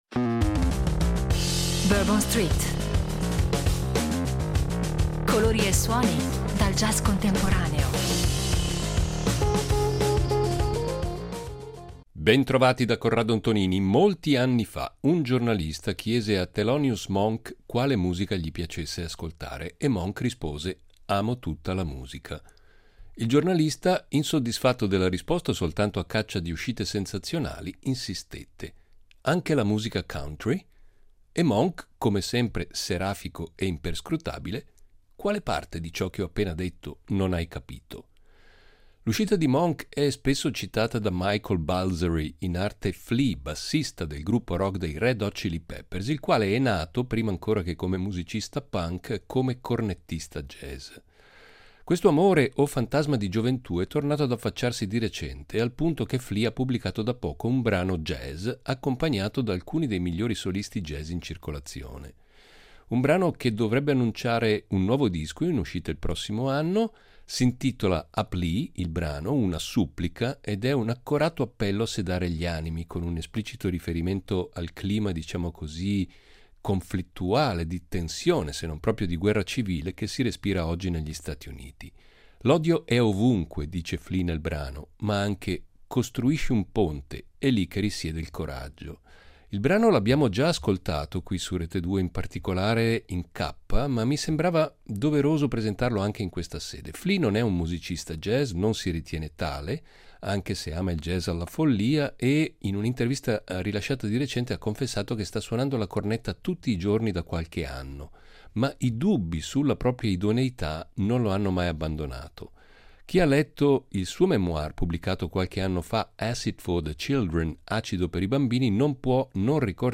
Non meno stimolante il progetto del gruppo marocchino basato a New York Saha Gnawa, che dal canto suo ospita musicisti jazz di spicco come Donny McCaslin, Nels Cline o Gilad Hekselman.